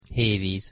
Aussprache von Hévíz auf Ungarisch [hu]
Männlich aus Ungarn